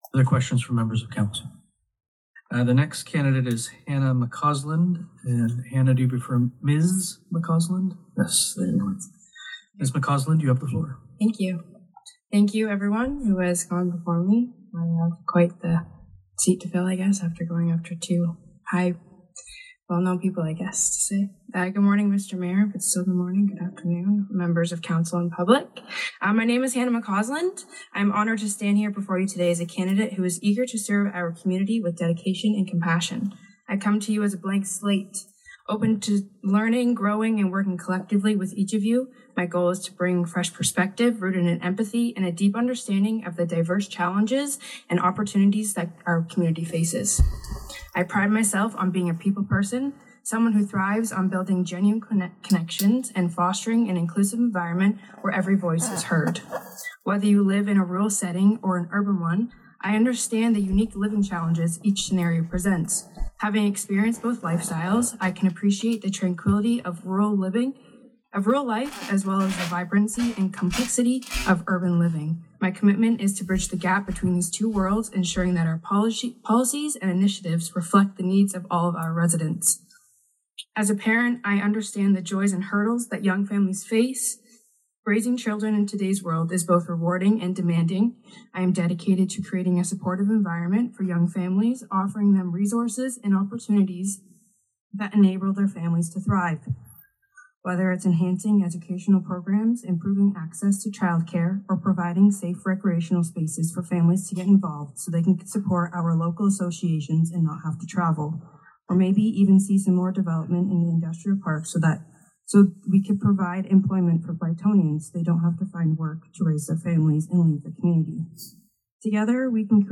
In this segment of In Their Words, you can hear MacAusland’s presentation to the council, followed by the council’s debate as politicians made their choices.